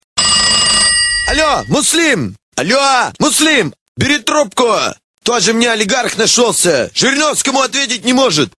Рингтоны пародии